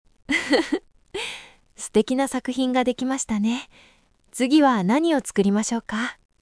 キャラクター設定と演技指定による多彩な音声生成：
-v Aoede -c "優しいお姉さん" -d "穏やかに微笑みながら" |
narration-gentle.mp3